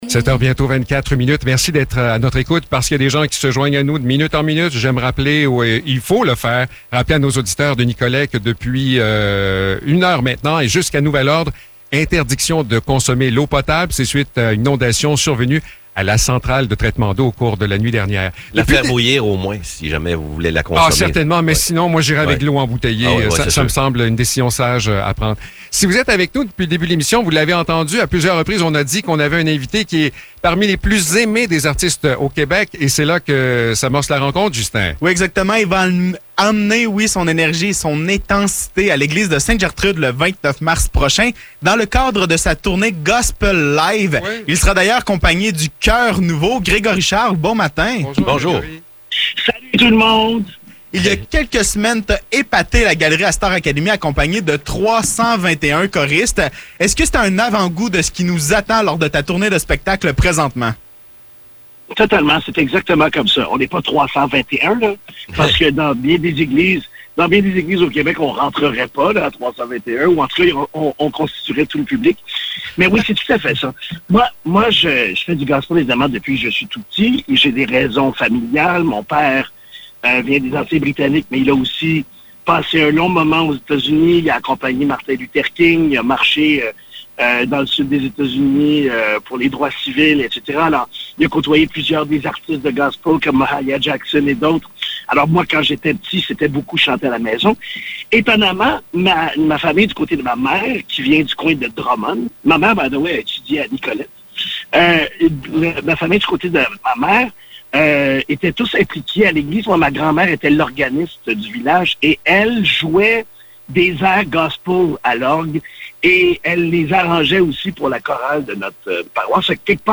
Entrevue avec Gregory Charles
Entrevue avec Gregory Charles concernant son spectacle à Ste-Gertrude le 29 mars prochain. Un invité surprise s’invite à la discussion.